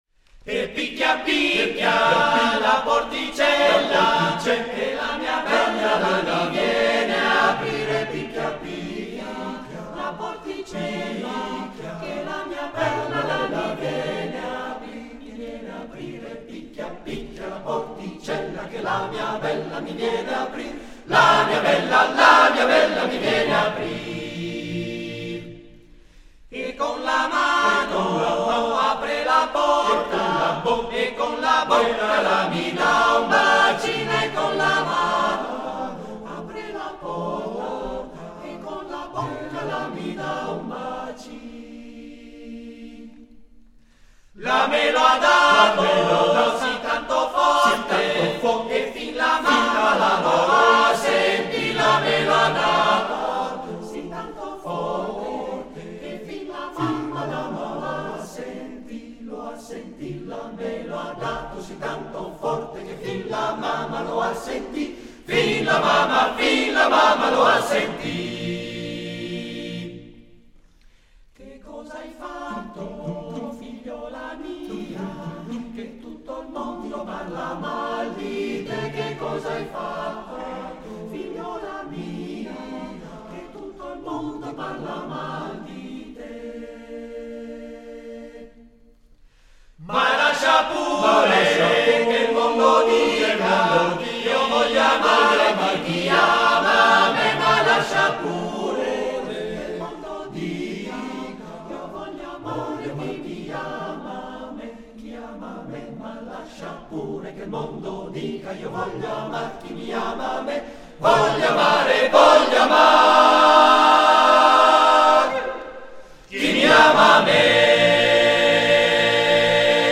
Arrangiatore: Pigarelli, Luigi (Armonizzatore)
Esecutore: Coro CAI Uget
: Registrazione live